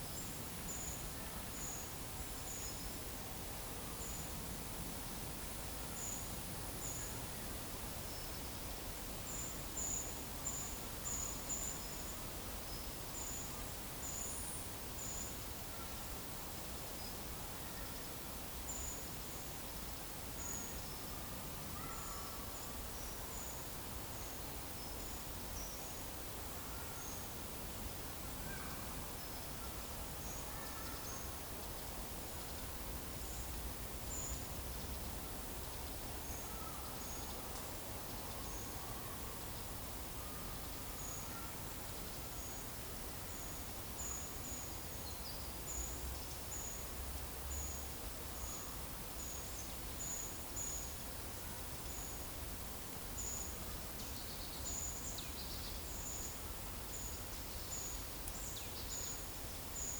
Monitor PAM
Certhia familiaris
Poecile palustris
Certhia brachydactyla
Turdus iliacus